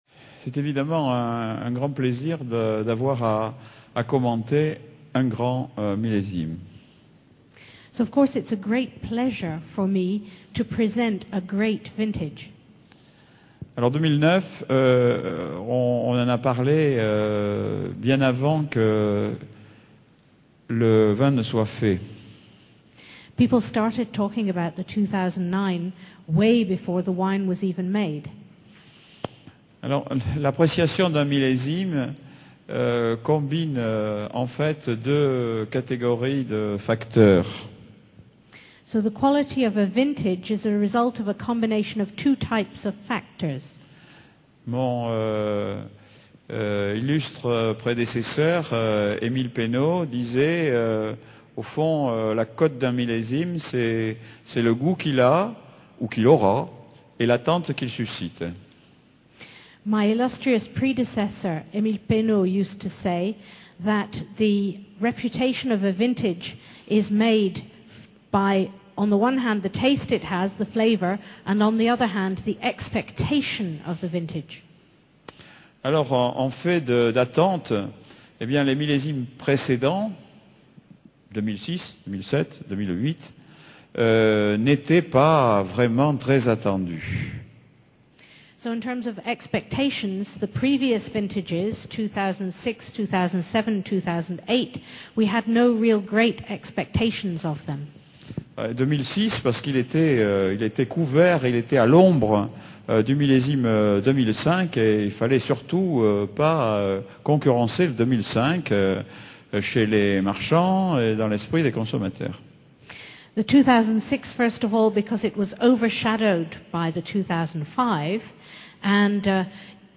Conférence bilingue français-anglais